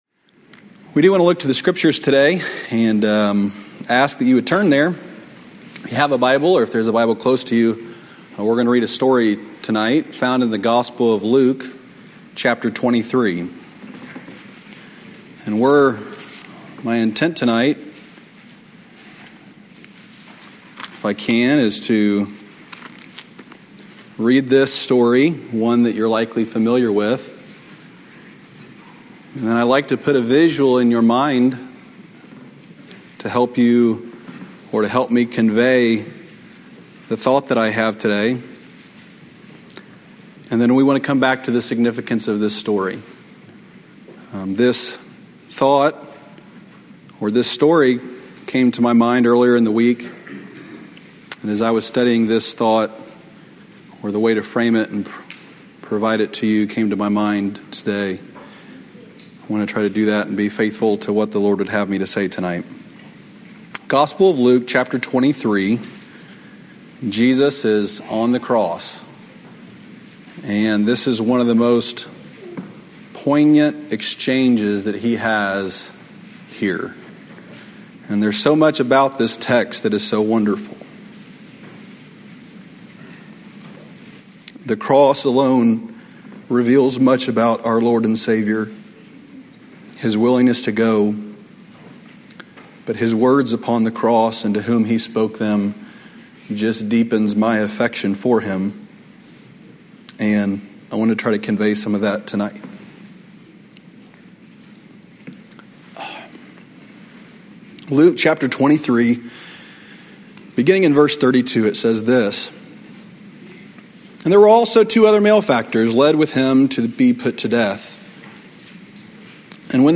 Sermons preached during special evening services - usually evangelistic.